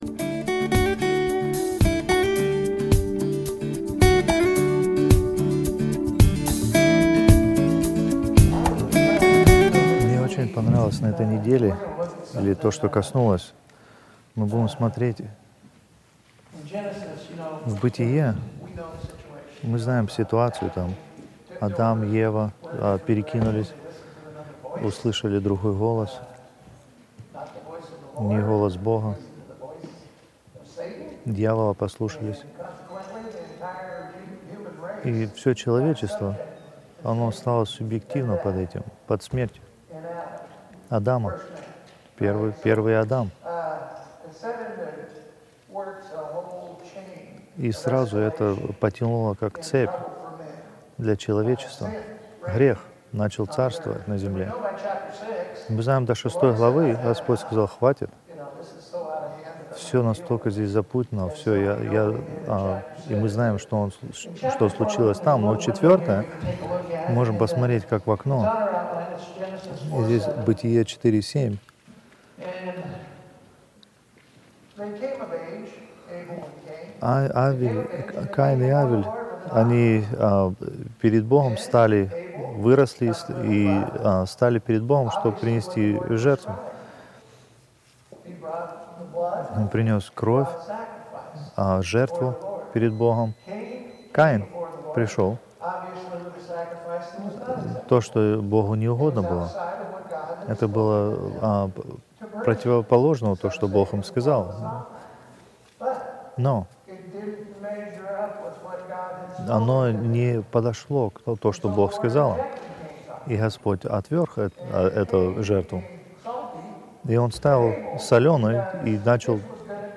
Русские проповеди